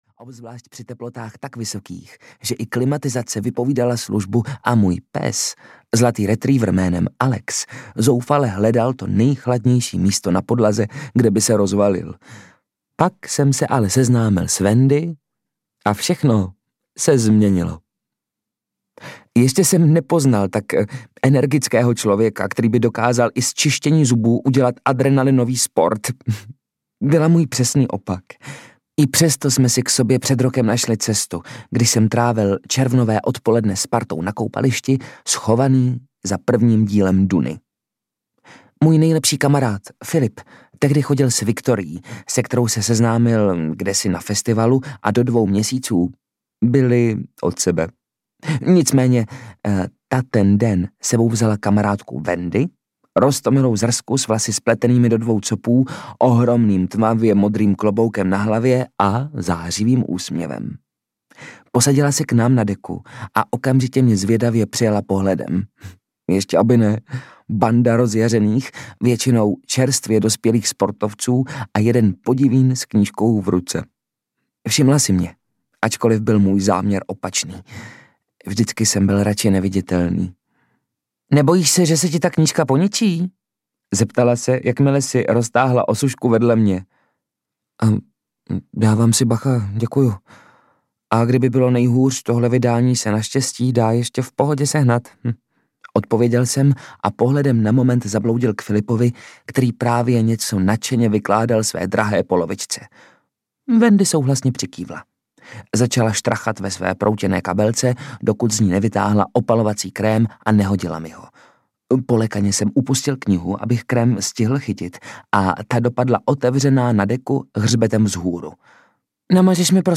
Léto mezi řádky audiokniha
Ukázka z knihy